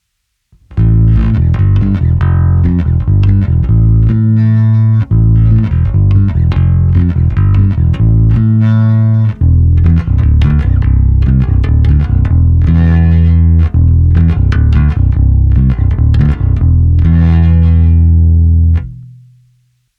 Nahrávky jsou bez simulace aparátu, nicméně jsou prohnané skrz kompresor a lampový preamp a použil jsem individuálně pro každou nahrávku i korekce přímo na baskytaře. Použité struny jsou nové niklové pětačtyřicítky Elixir.
Cívka 1 – zvuk ala Precision